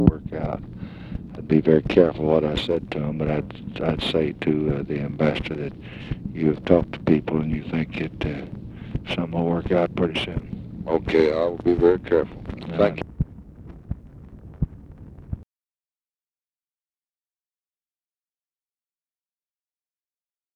Conversation with UNIDENTIFIED MALE, September 28, 1965
Secret White House Tapes